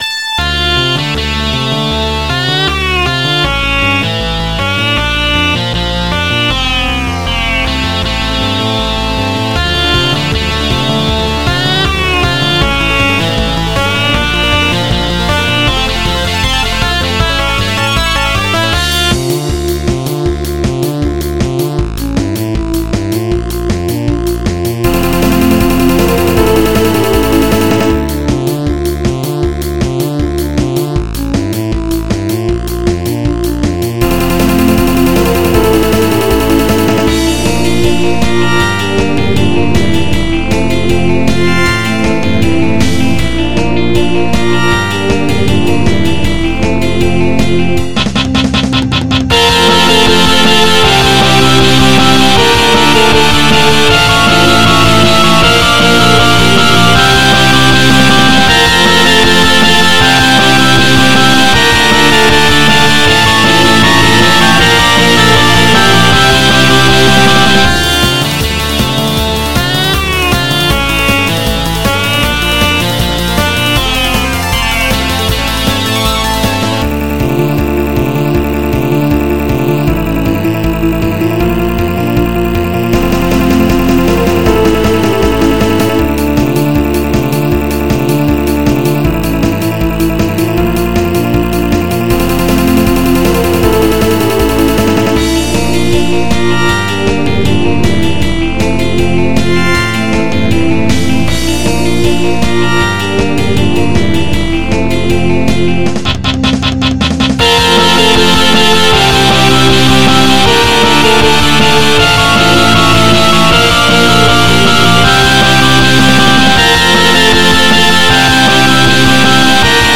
MIDI 82.39 KB MP3
pretty fast paced song